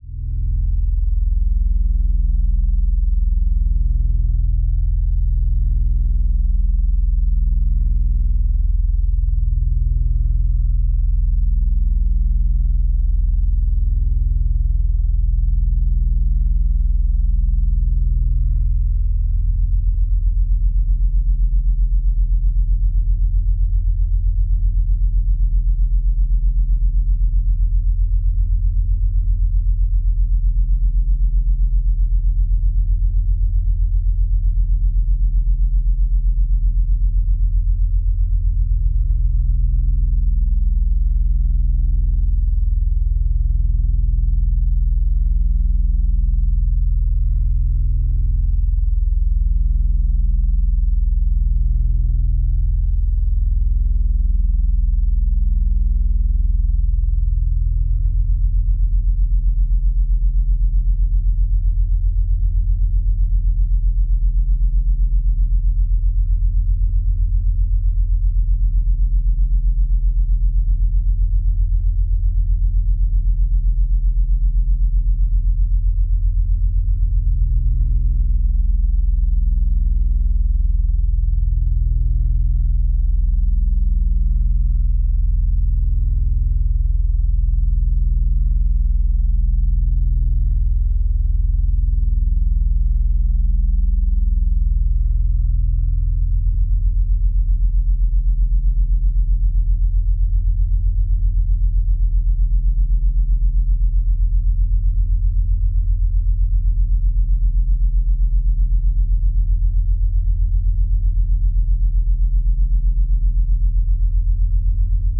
Low Drone
Two low dissonant notes used to create a sense of unease during the “doctor” scenes.